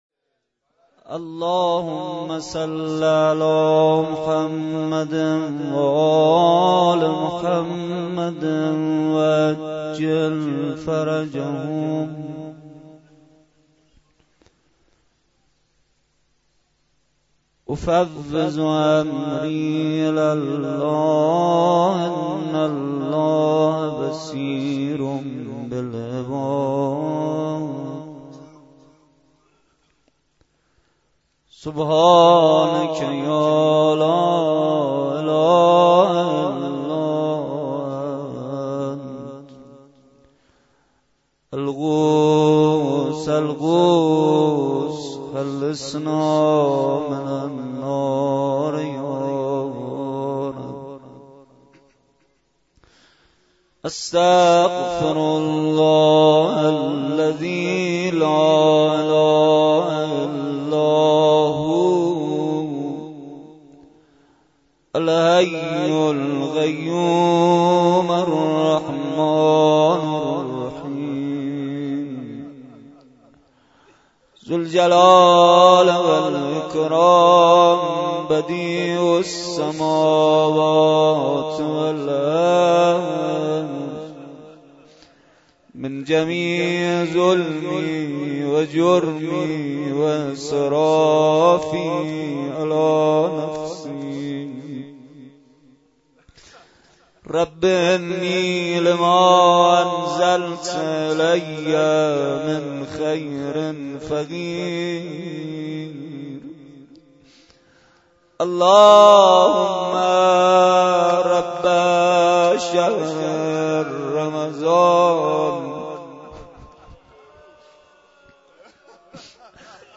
مراسم شب های بیست ویکم الی سی ام ماه رمضان با مداحی کربلایی محمدحسین پویانفر کهف الشهداء برگزار گردید.